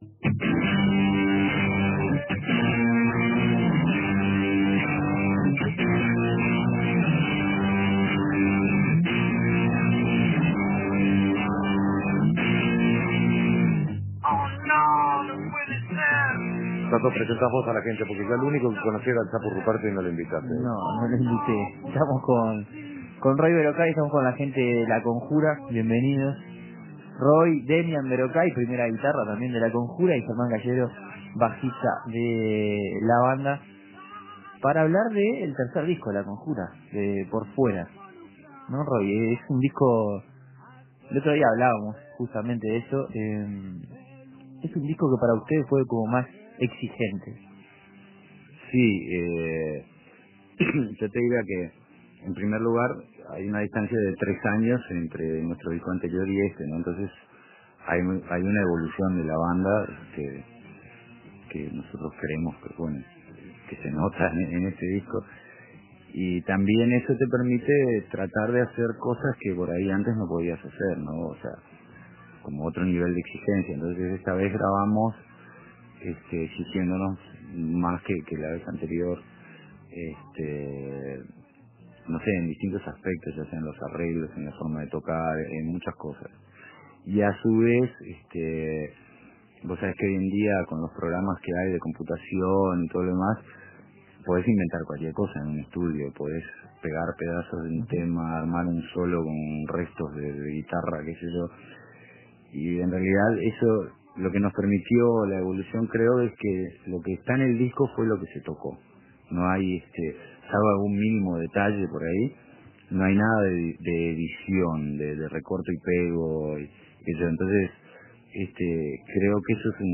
La banda de Roy Berocay vino a La Brújula y habló sobre este álbum que llega a tres años de su último lanzamiento discográfico y viene con una gran evolución de la banda y con un nivel de exigencia más alto a la hora de grabarlo.